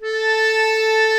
A4 ACCORDI-R.wav